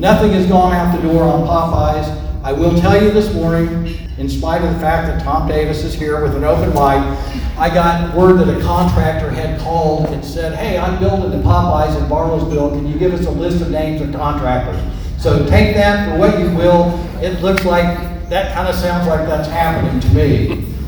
The installation and the presentations took place at the Green Country Republican Women's Club Luncheon on Thursday, March 25, at Hillcrest Country Club in Bartlesville.